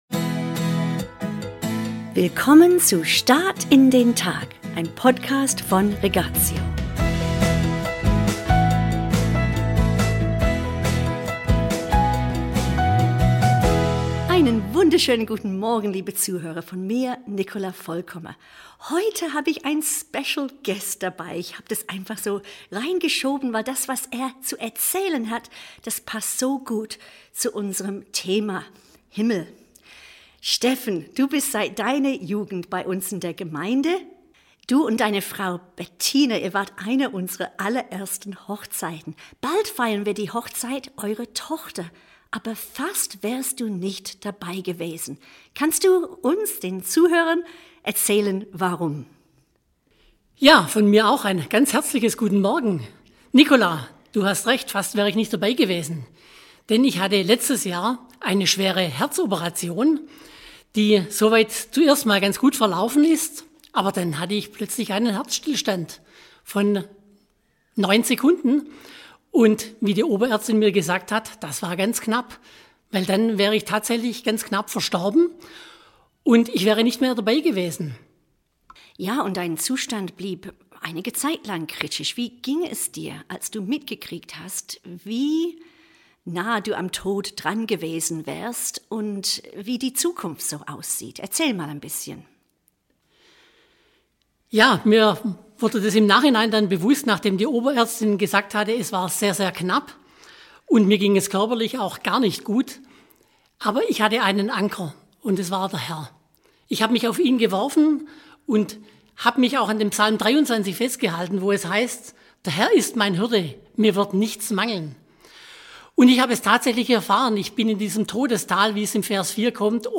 Interview – ein Herzversagen mit ungewissem Ausgang